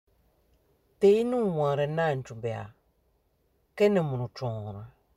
Lecture et prononciation